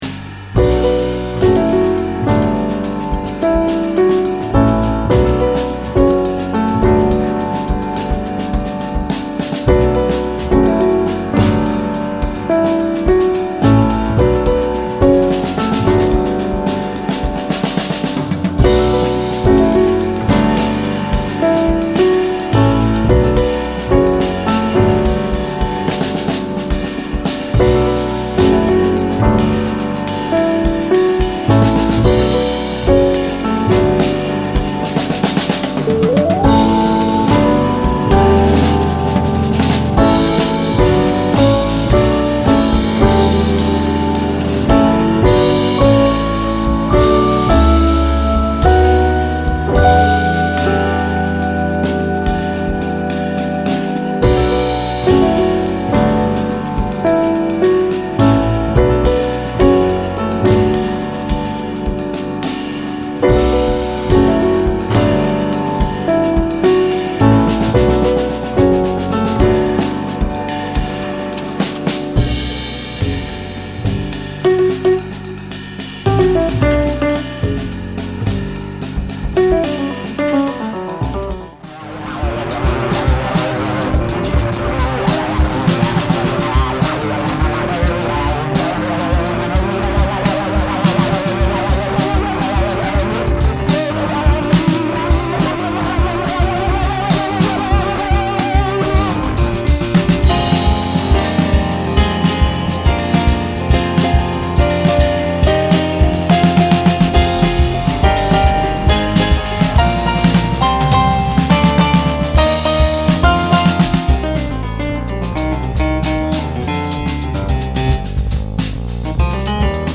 piano
drums
bass.